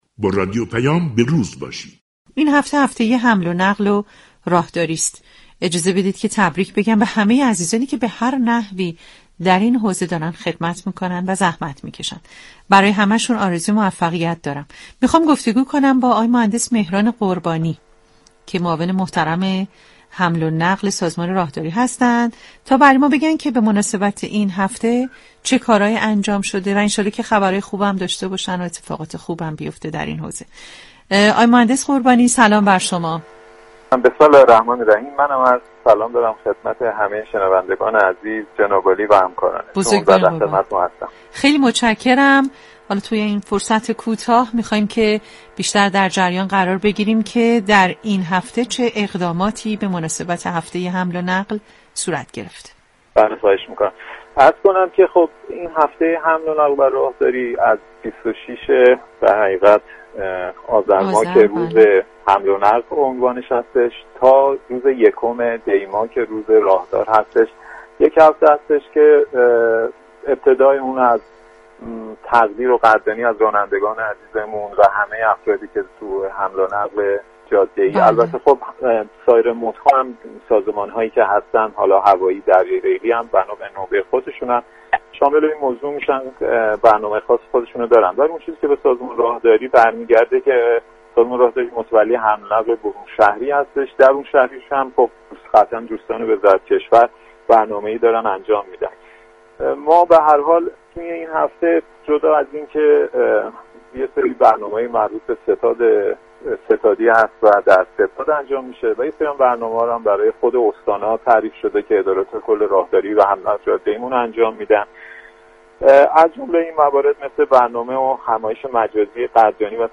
قربانی ، معاون حمل و نقل سازمان راهداری و حمل و نقل جاده ای ، در گفتگو با رادیو پیام ، جزئیاتی از اقدامات سازمان راهداری در هفته حمل و نقل را بیان كرد .